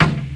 • 90's High Floor Reggae Tom Drum Sound D# Key 21.wav
Royality free tom sound tuned to the D# note. Loudest frequency: 870Hz
90s-high-floor-reggae-tom-drum-sound-d-sharp-key-21-BUN.wav